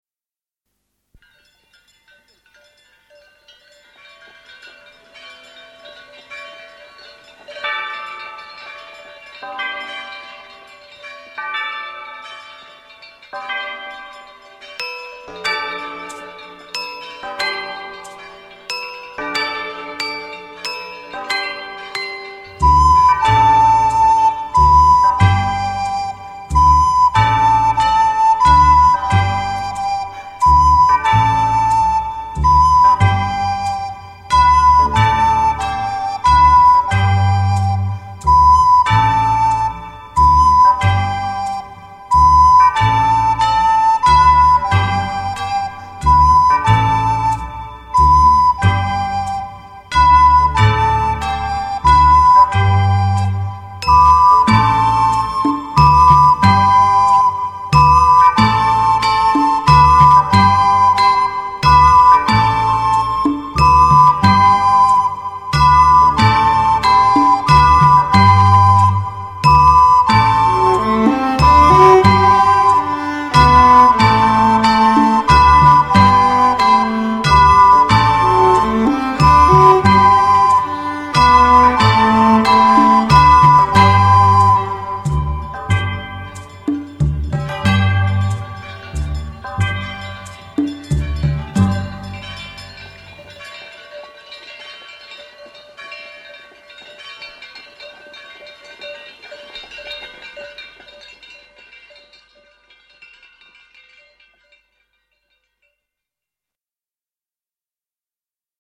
Audicions de flauta